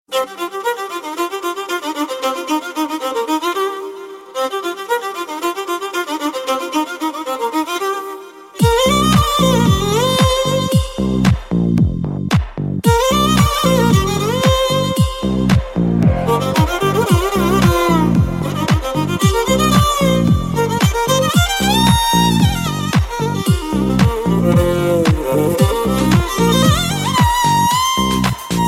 Thể loại nhạc chuông: Nhạc không lời